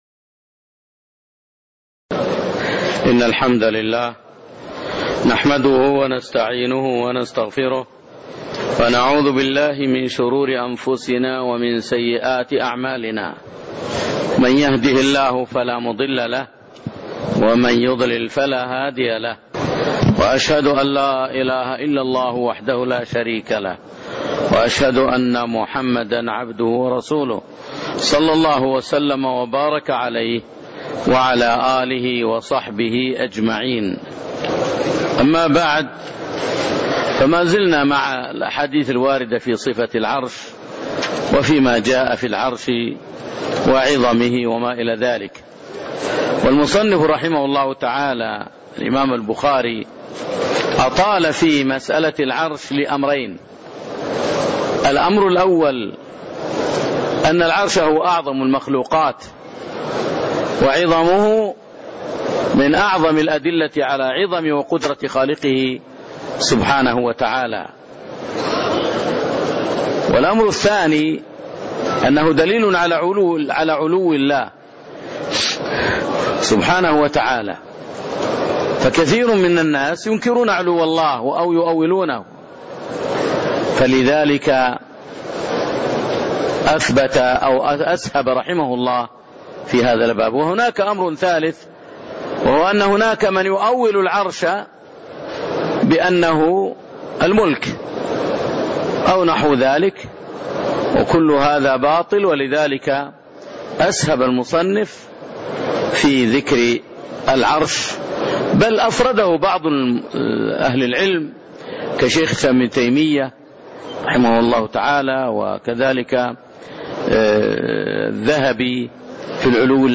تاريخ النشر ١٣ ربيع الثاني ١٤٣٤ هـ المكان: المسجد النبوي الشيخ